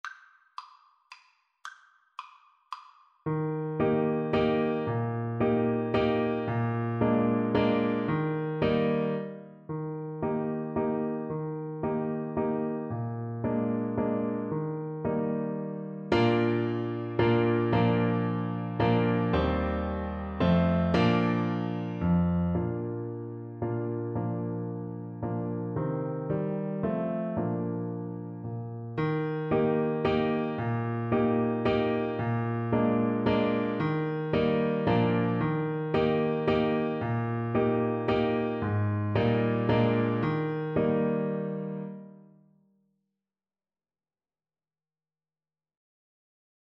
Clarinet
Allegro = c. 112 (View more music marked Allegro)
3/4 (View more 3/4 Music)
Eb major (Sounding Pitch) F major (Clarinet in Bb) (View more Eb major Music for Clarinet )
Traditional (View more Traditional Clarinet Music)